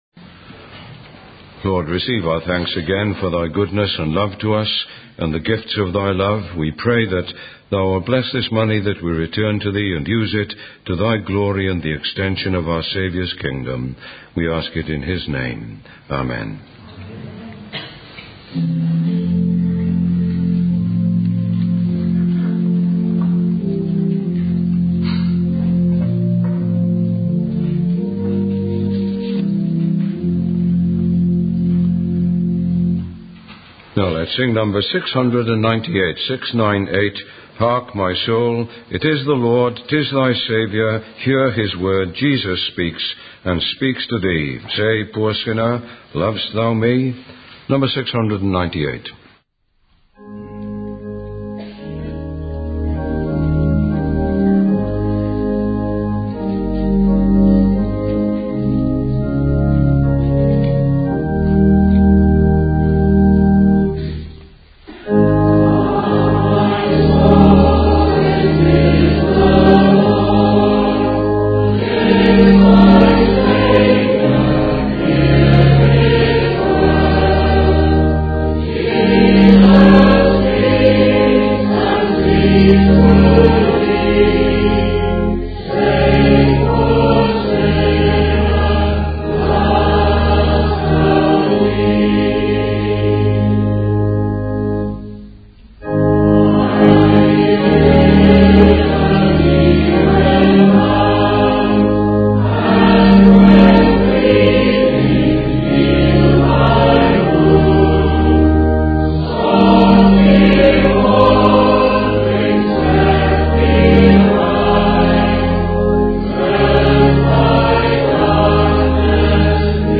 Sermons 2007